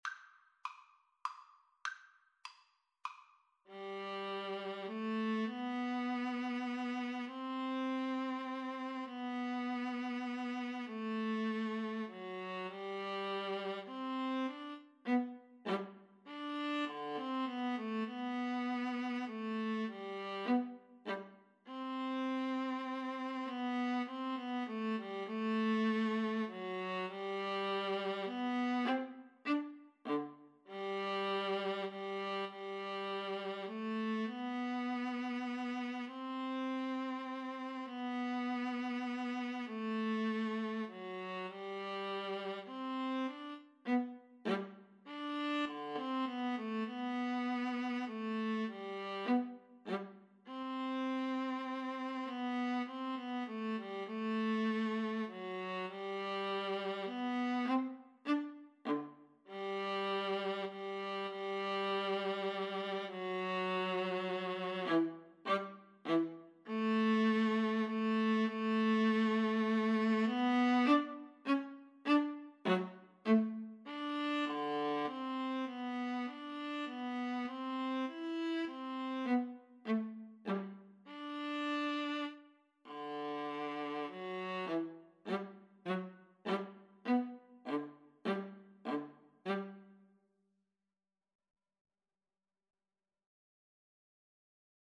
3/4 (View more 3/4 Music)
Classical (View more Classical Violin-Viola Duet Music)